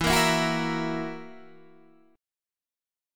E Suspended 2nd Flat 5th